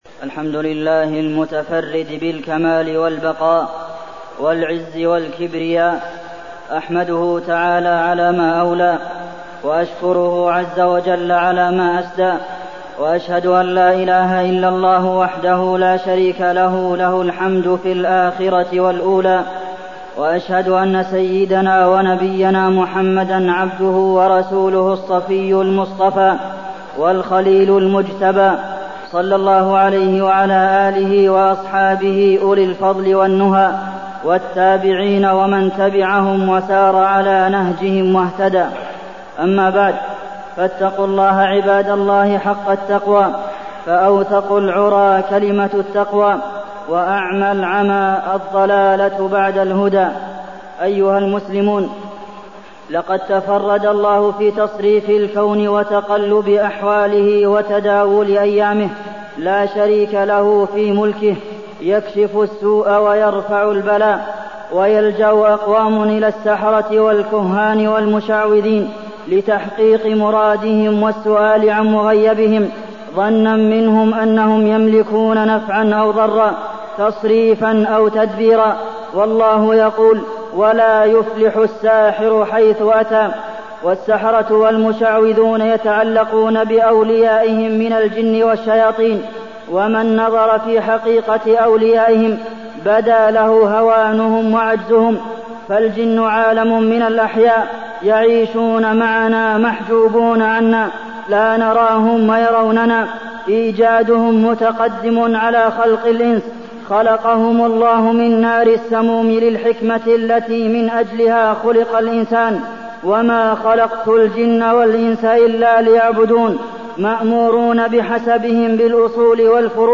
تاريخ النشر ٢١ جمادى الآخرة ١٤٢٠ هـ المكان: المسجد النبوي الشيخ: فضيلة الشيخ د. عبدالمحسن بن محمد القاسم فضيلة الشيخ د. عبدالمحسن بن محمد القاسم الجن والشياطين